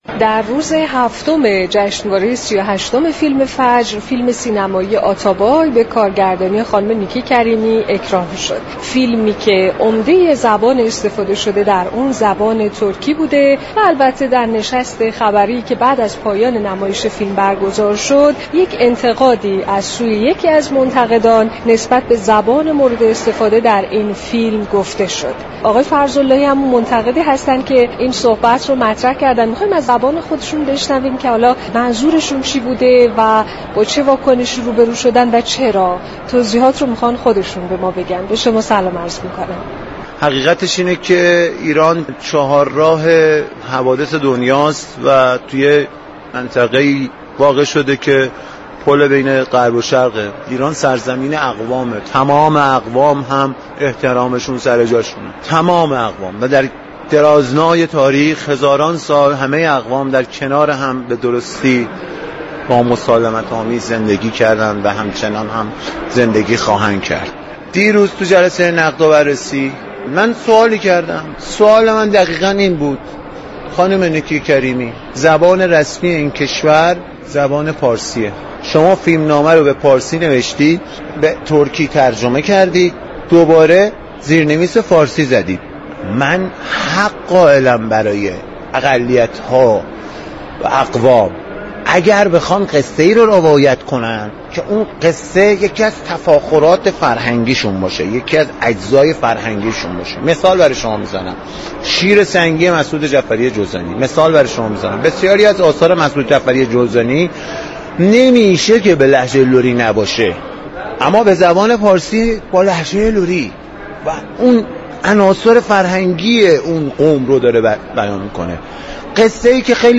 گفت و گو كرده است.